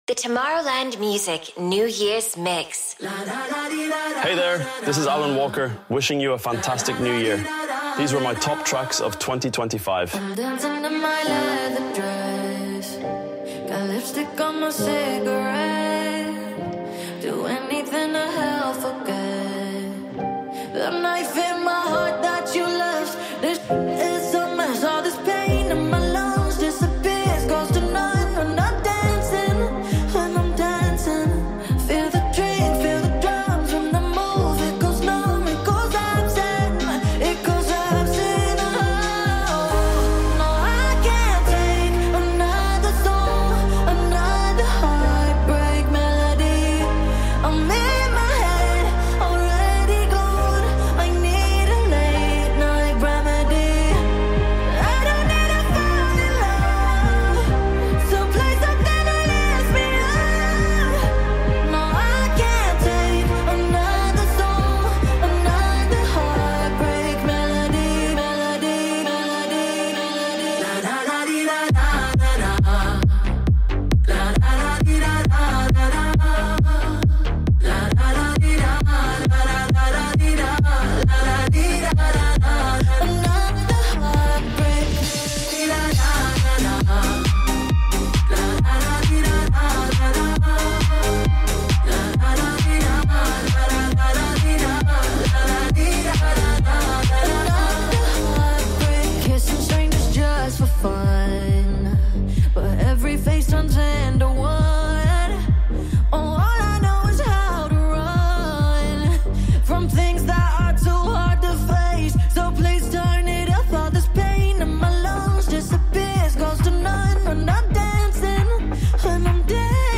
House mix